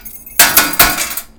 KLINKLANG.mp3